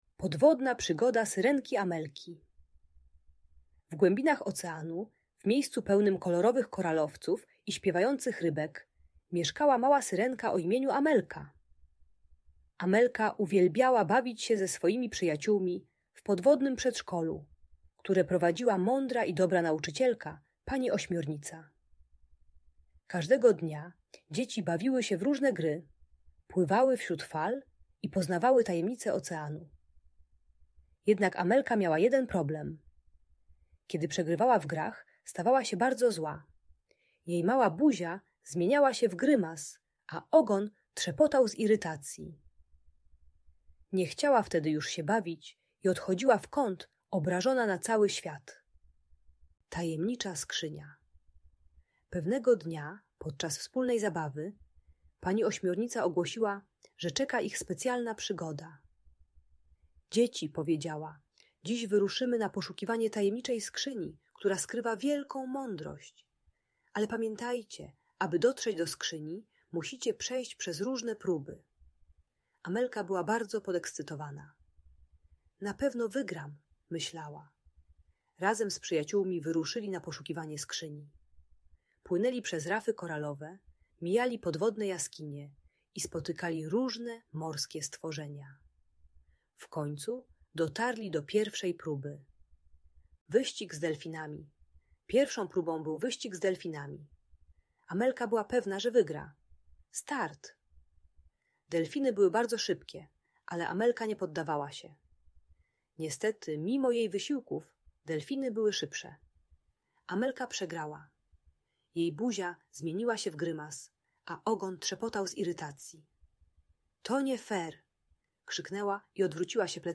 Podwodna Przygoda Syrenki Amelki - Bunt i wybuchy złości | Audiobajka